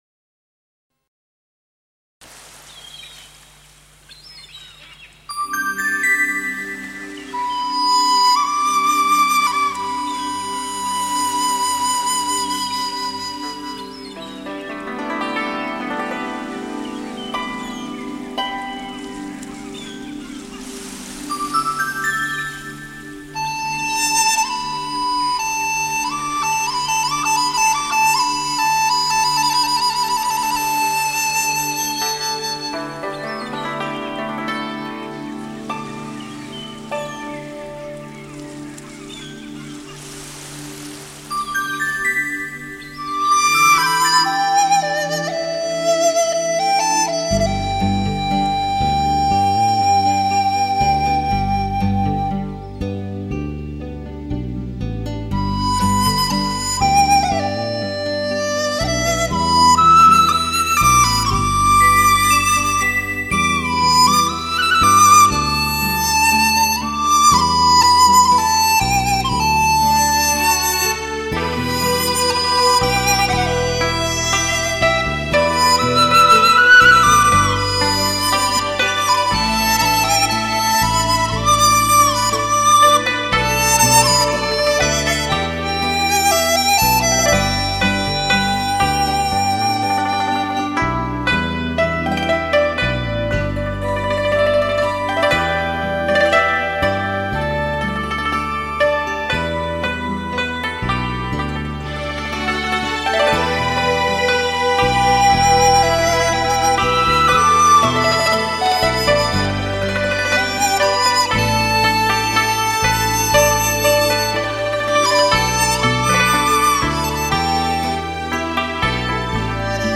超逼真音效采样技术
超广场音效
竹笛、古筝